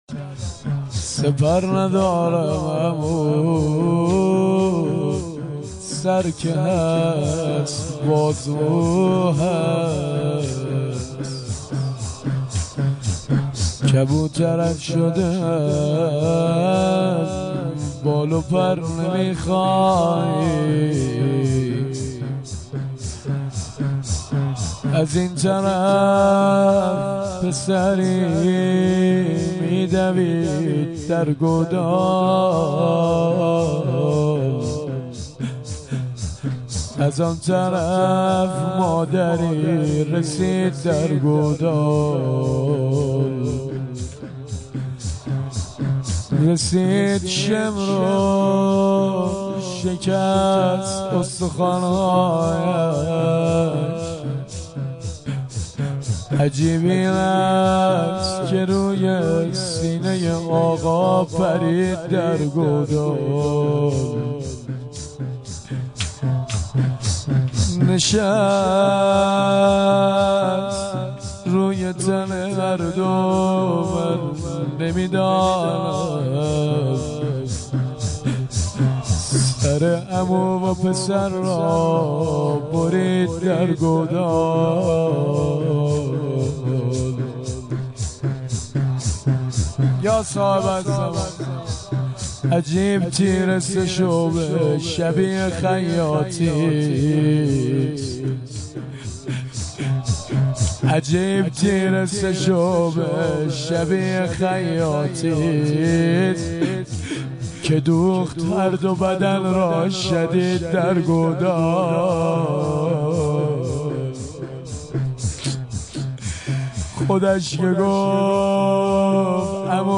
نغمه
هیئت عاشقان ثارالله